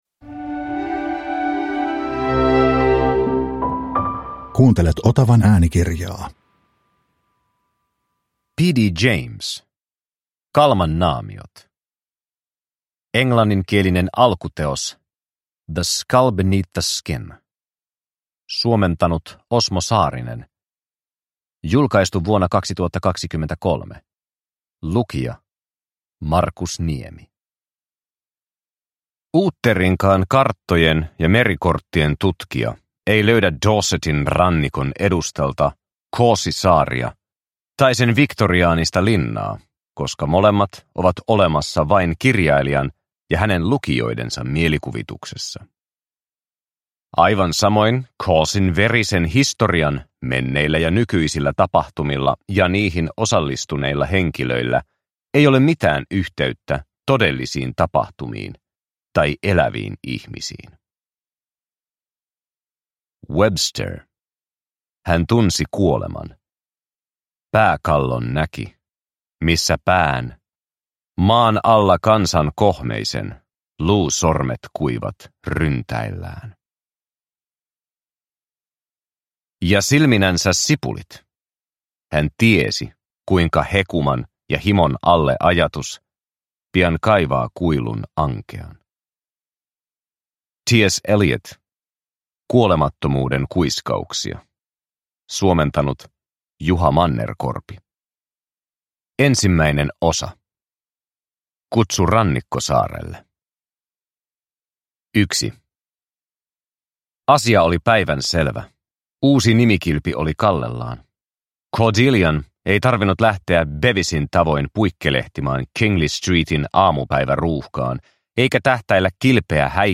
Kalman naamiot – Ljudbok – Laddas ner